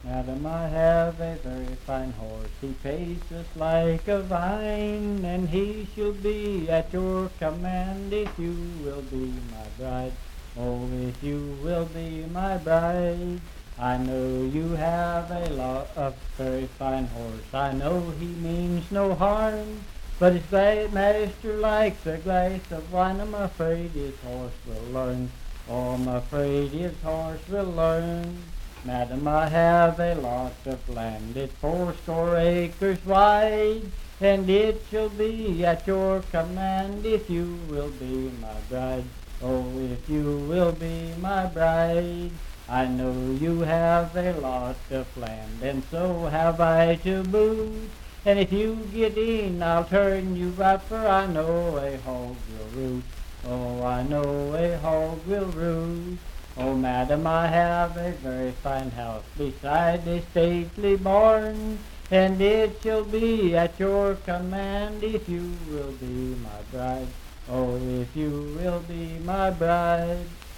Unaccompanied vocal music
Dance, Game, and Party Songs
Voice (sung)